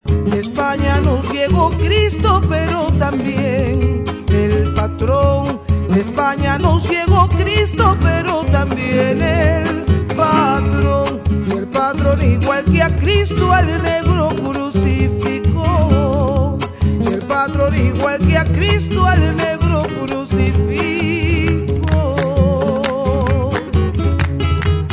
guitarra
percusión